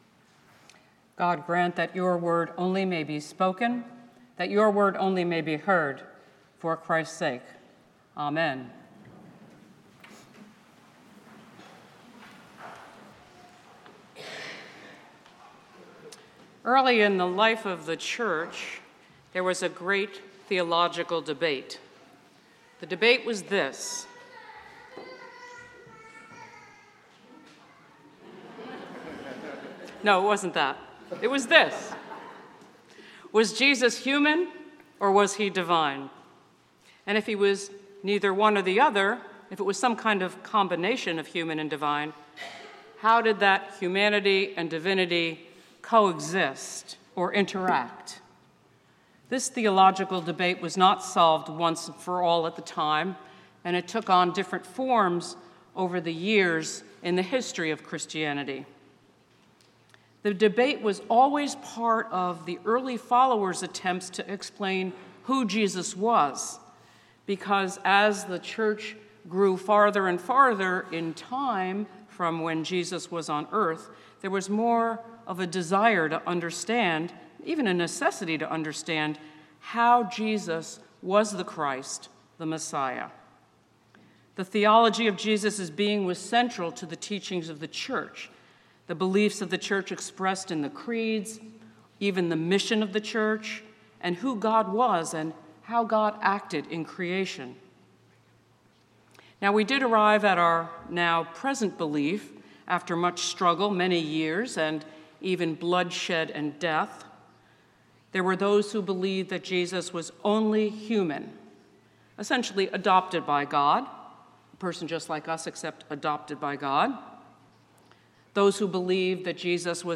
St-Pauls-HEII-9a-Homily-22MAR26.mp3